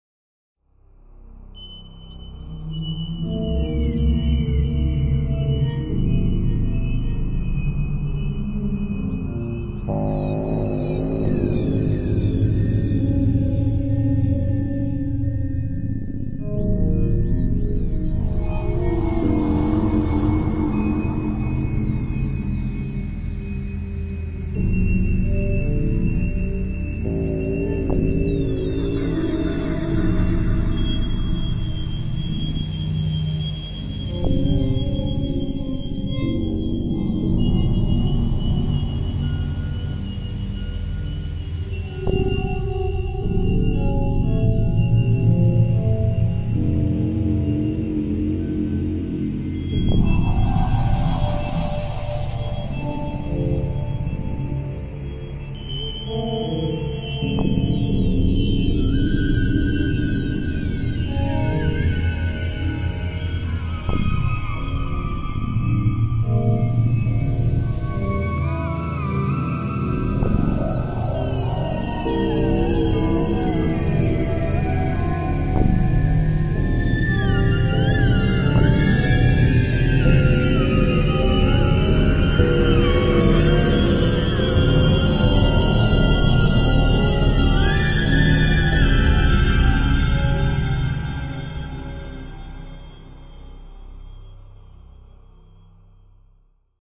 creepy_background.mp3